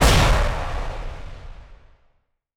hit2.wav